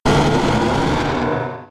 Cri de Méga-Dardargnan K.O. dans Pokémon Rubis Oméga et Saphir Alpha.